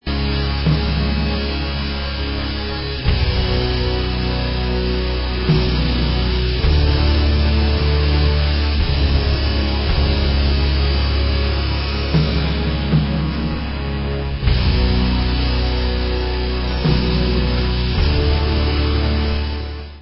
Extreme doom